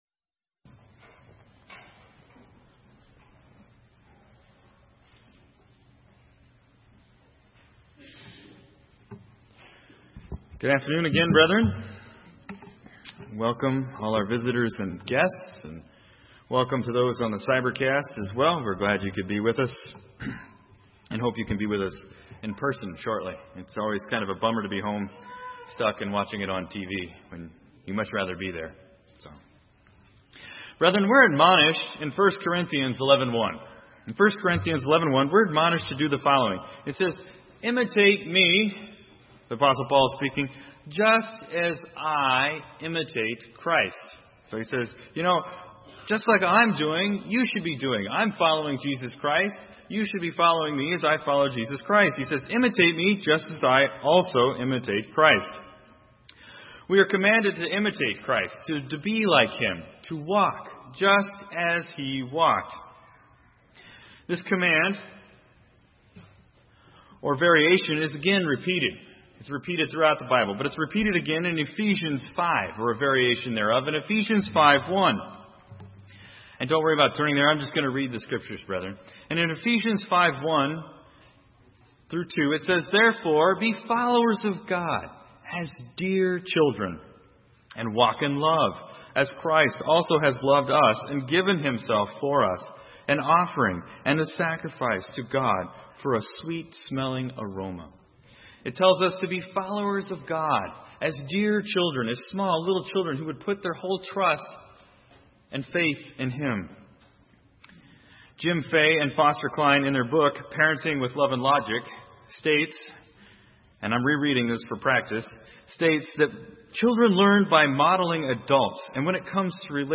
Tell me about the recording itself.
Given in Salem, OR